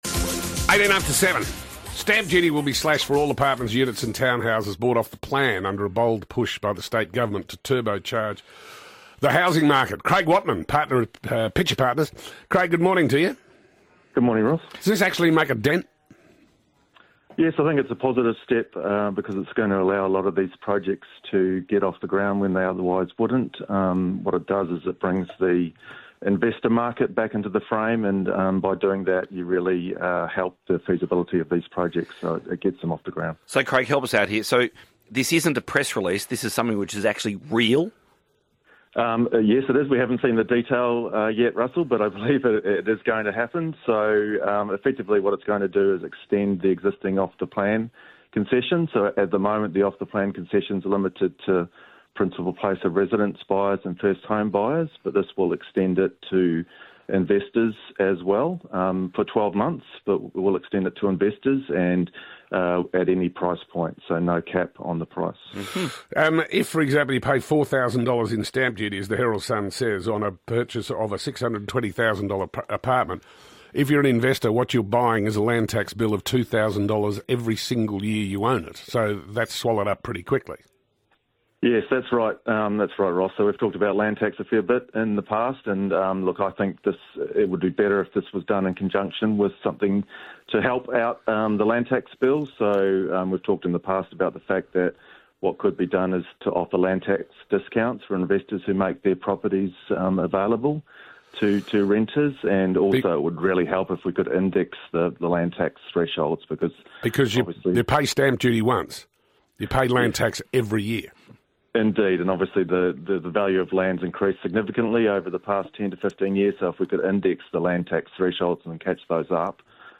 Interview: Stamp duty slashed on off-the-plan apartments and units in Victoria